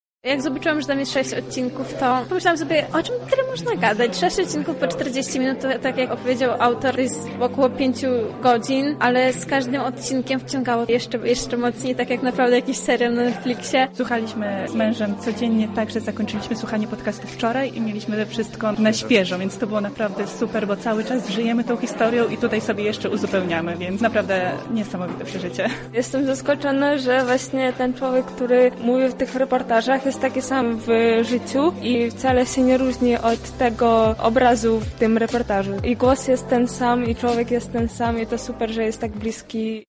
Zapytaliśmy uczestników o wrażenia.